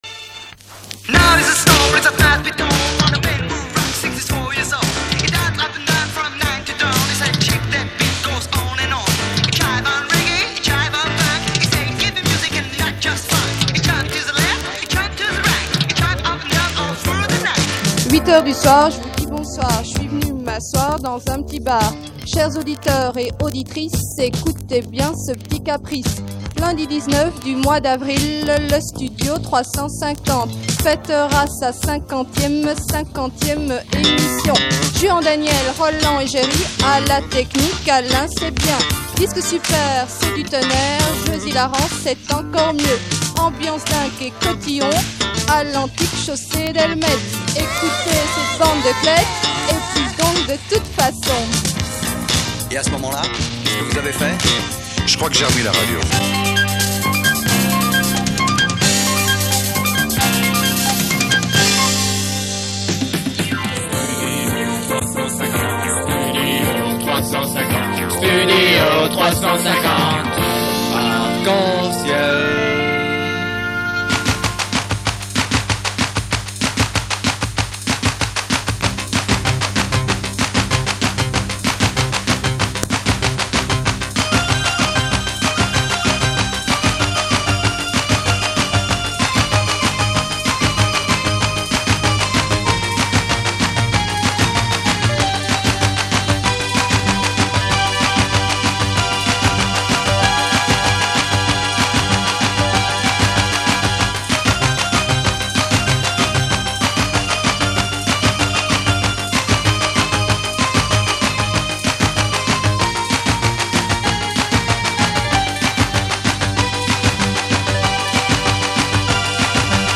50�me �mission de Studio 350 en direct de l'Antique - 19 avril 1982